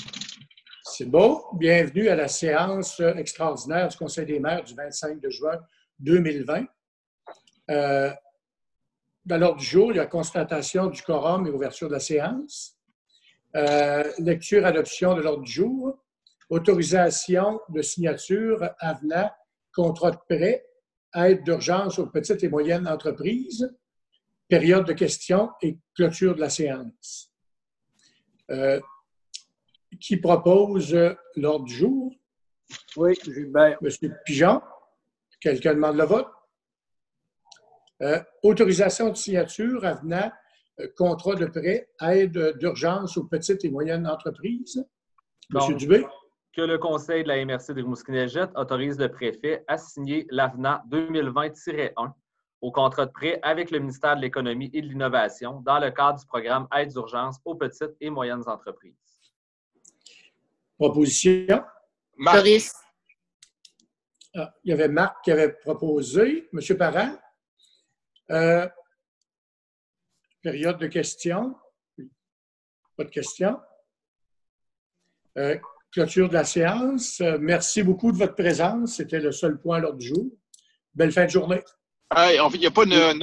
Voici l’audio de la séance spéciale du conseil de la MRC de Rimouski-Neigette, tenue le 25 juin 2020.